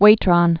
(wātrŏn, -trən)